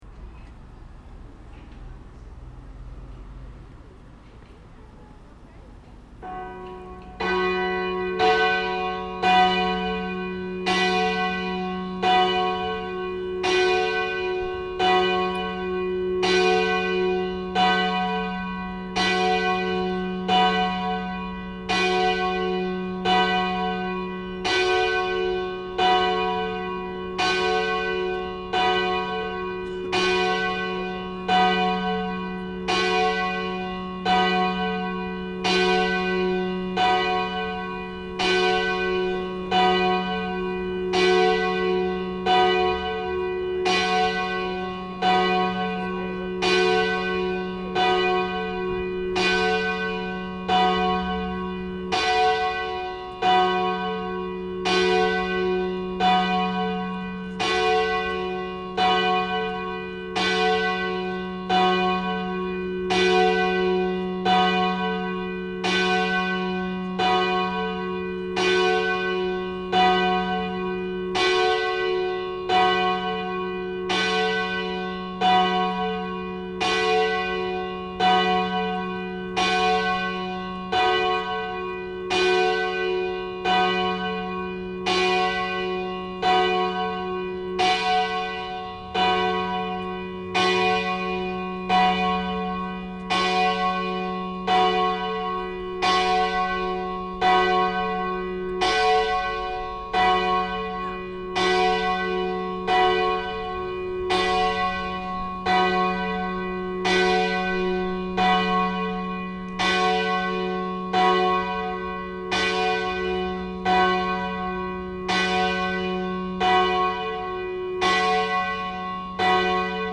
Það er klukka sem smíðuð var í Englandi árið 1940 og sett upp við byggingu kirkjunnar. Klukkan er 3 fet í þvermál, vegur 800 kg og hefur tóninn fís.
akureyrarkirkja_stora.mp3